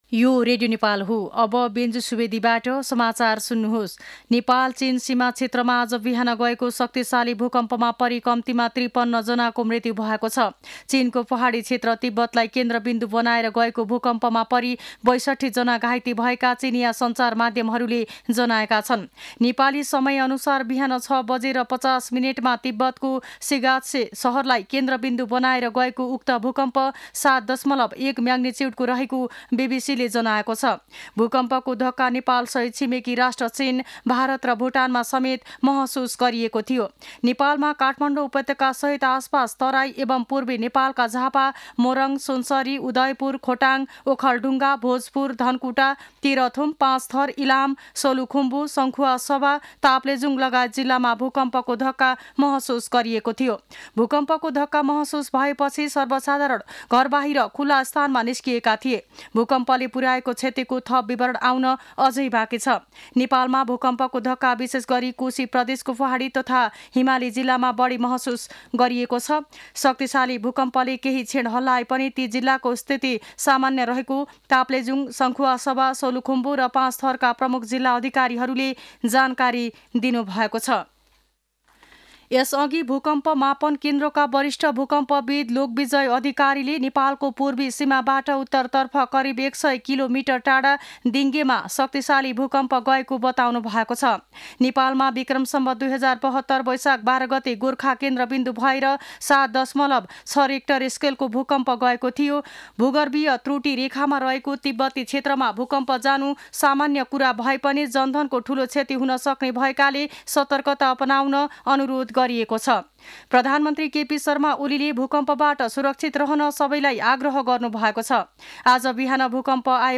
दिउँसो १ बजेको नेपाली समाचार : २४ पुष , २०८१
1-pm-news-1-1.mp3